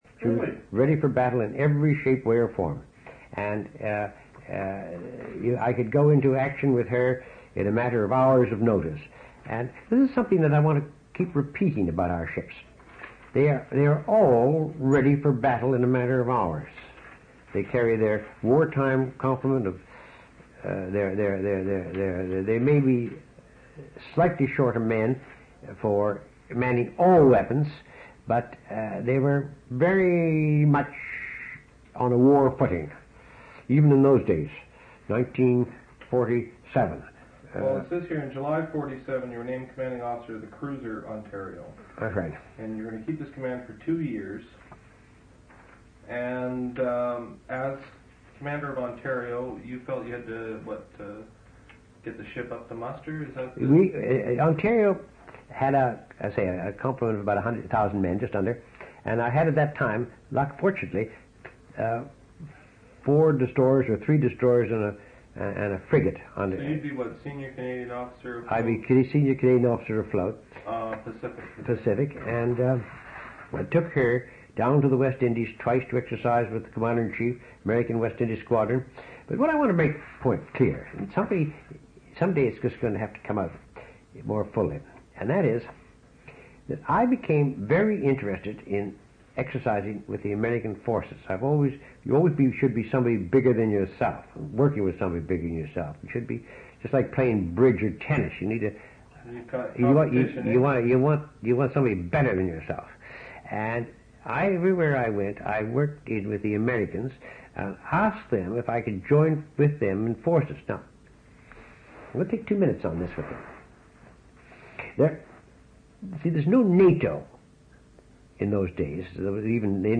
An interview/narrative